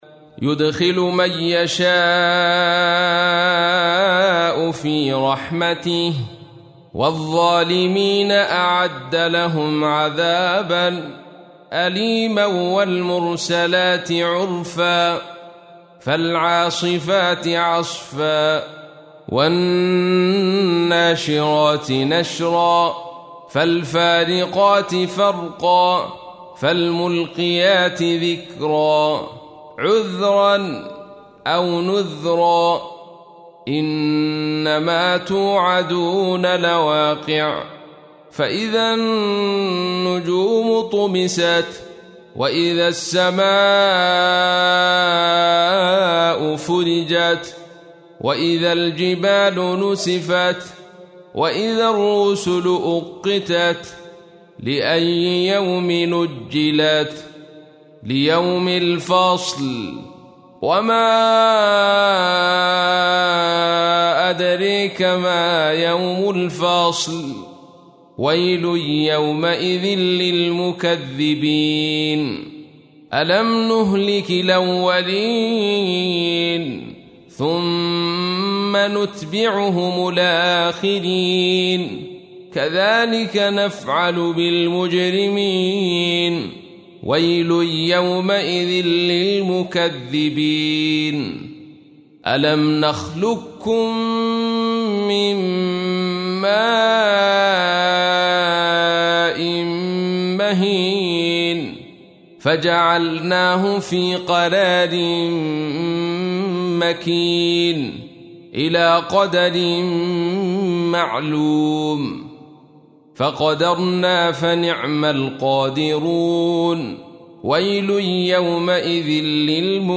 تحميل : 77. سورة المرسلات / القارئ عبد الرشيد صوفي / القرآن الكريم / موقع يا حسين